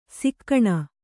♪ sikkaṇa